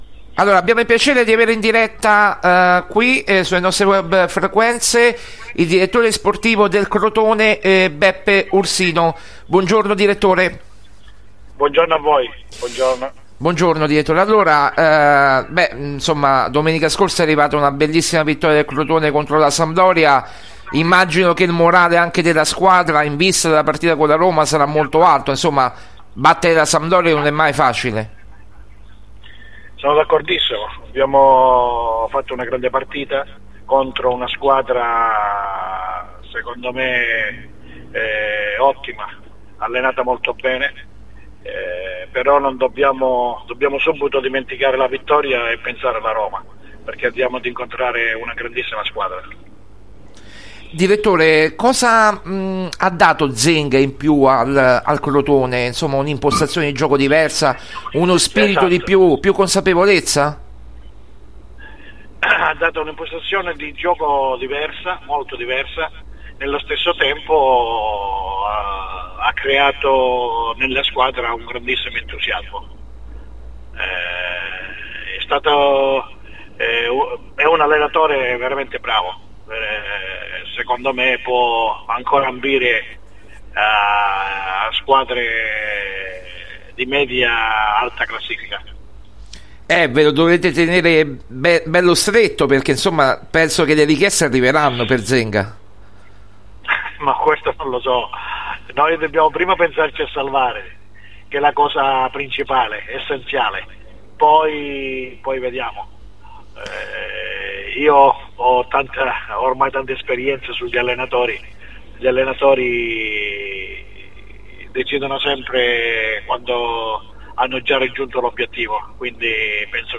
Calciomercato Esclusive Notizie